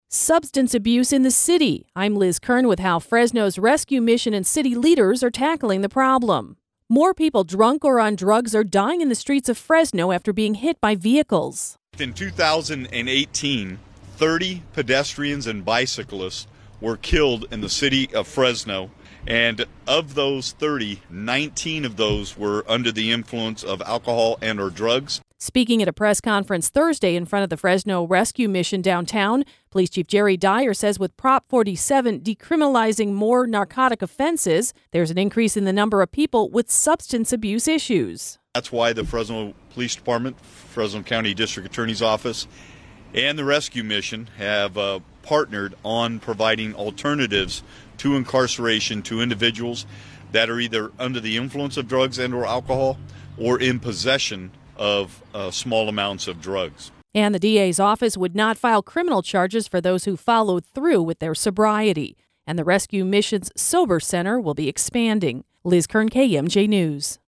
Fresno’s Police Chief Jerry Dyer and partners held a press conference Thursday in front of the Fresno Rescue Mission, downtown.